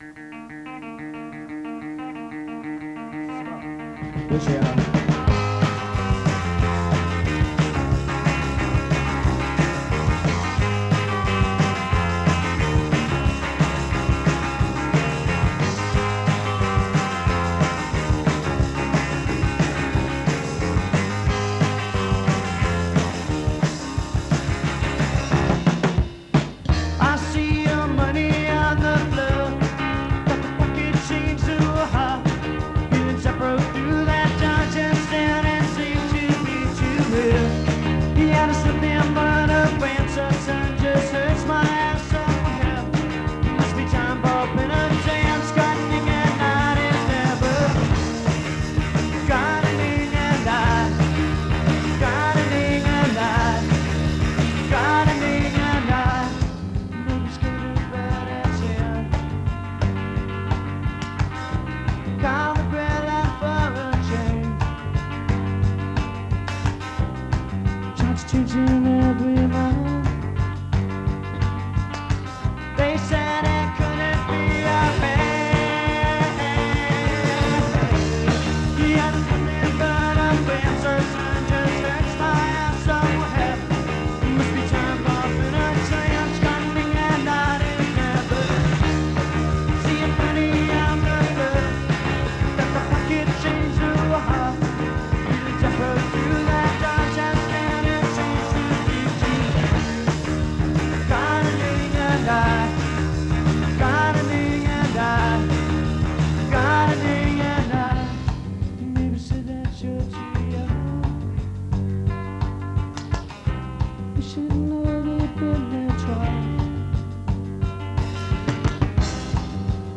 Merlins, Madision, WI